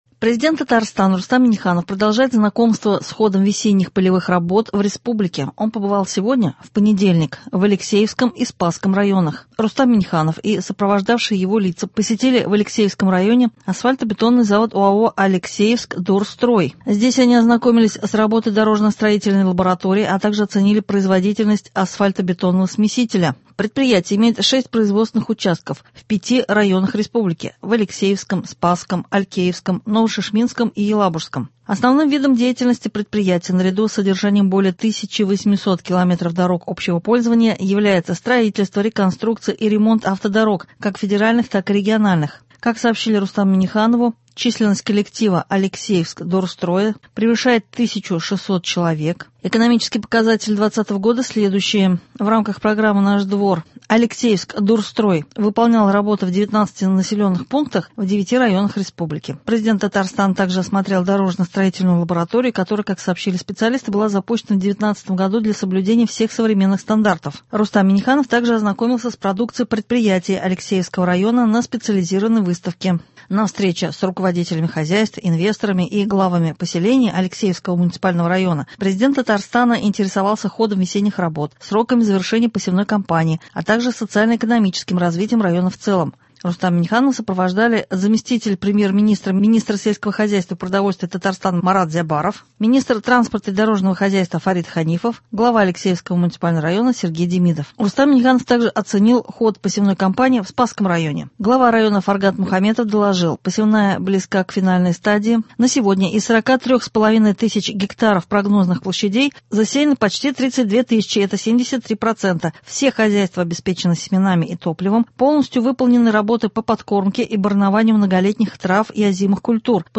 Новости (10.05.21) | Вести Татарстан